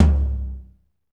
TOM A C F00L.wav